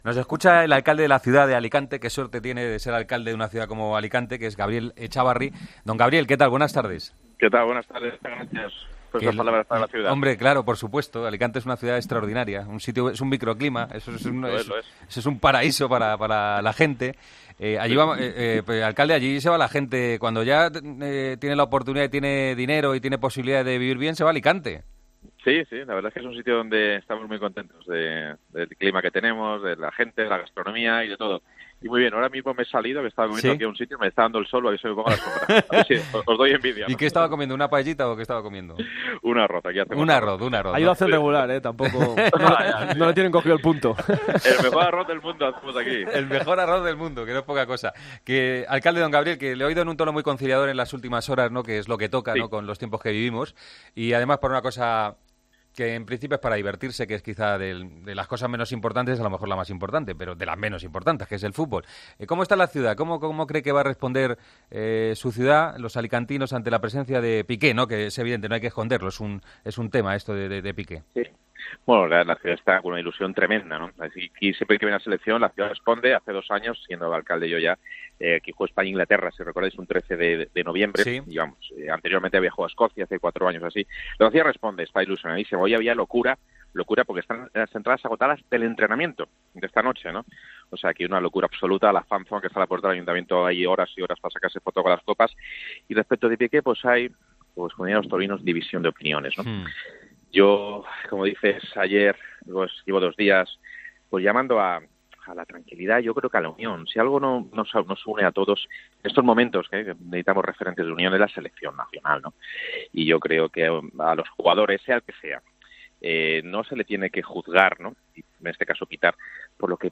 Hablamos con el alcalde de Alicante en la previa del España- Albania. Gabriel Echávarri habla de los pitos a Piqué y del ambiente que se espera en el Rico Pérez este viernes.